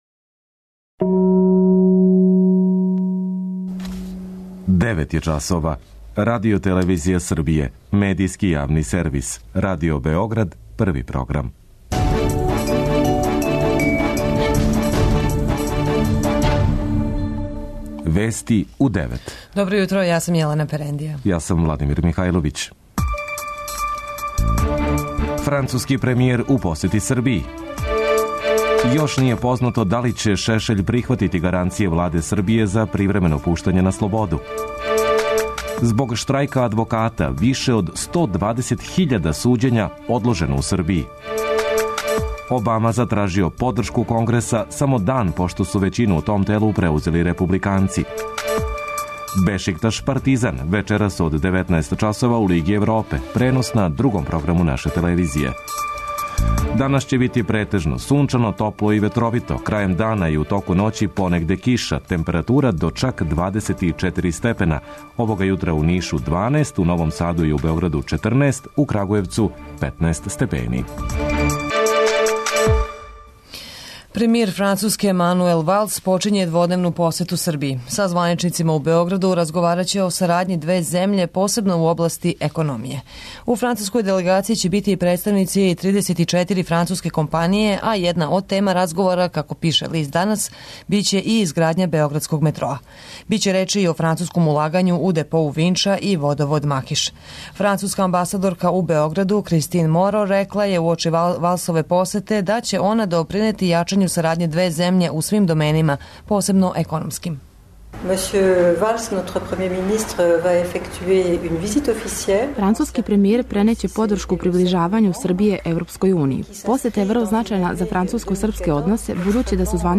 преузми : 9.86 MB Вести у 9 Autor: разни аутори Преглед најважнијиx информација из земље из света.